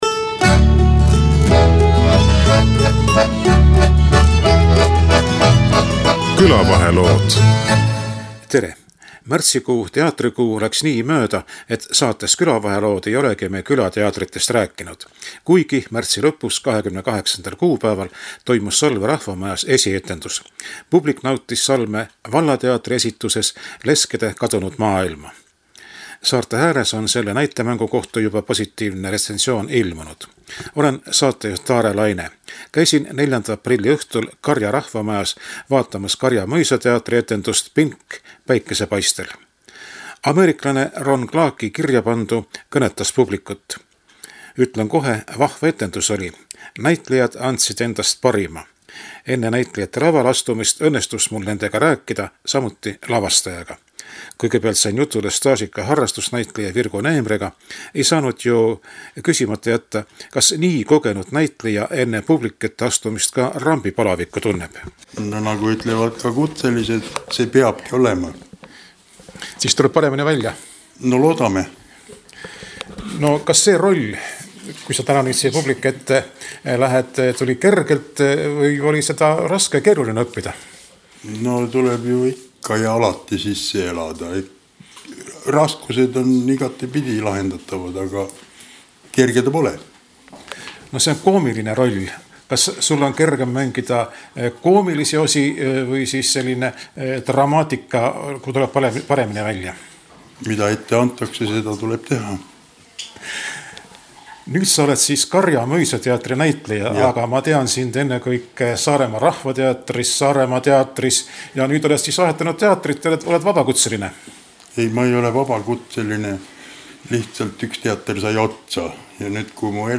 samuti teatri külastajaid. 8.aprillil etendati näitemängu Randvere seltsimajas.